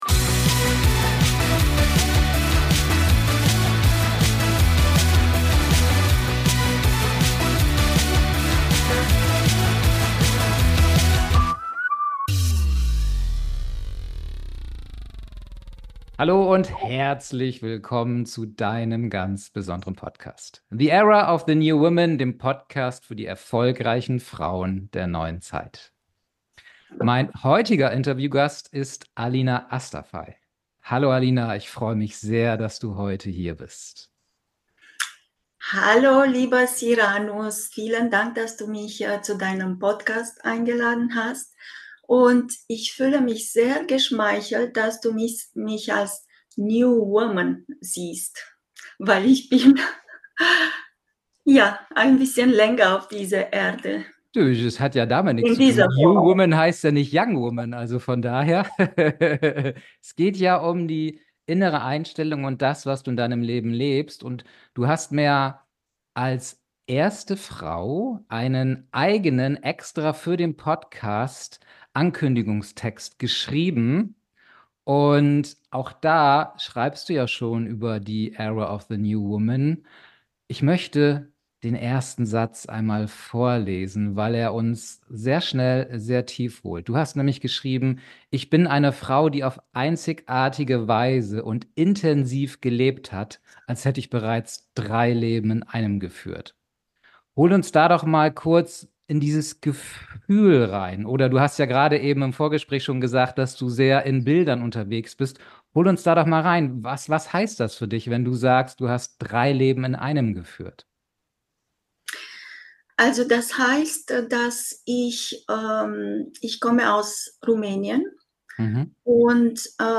#042 Wie ein metaphysisches Erlebnis alles veränderte. Das Interview mit der Olympia-Zweiten Alina Astafei ~ The Era of the New Women Podcast